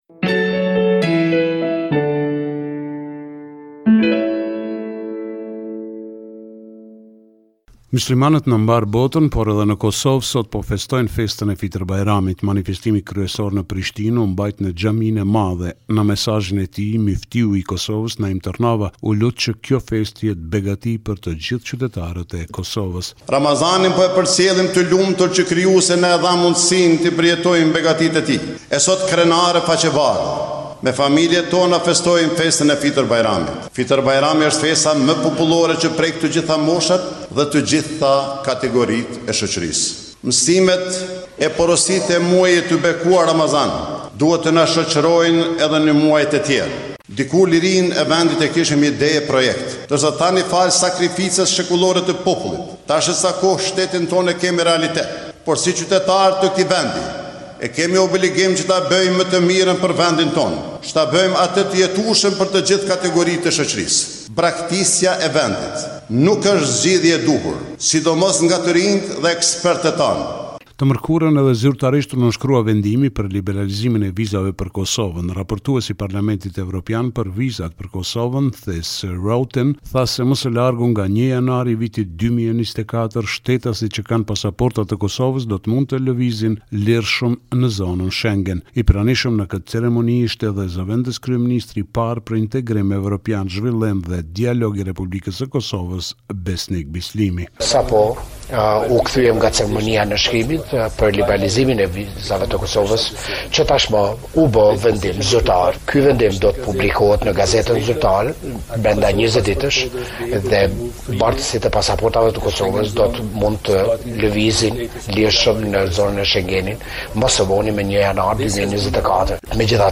Raporti me të rejat më të fundit nga Kosova.